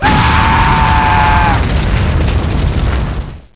Flames.wav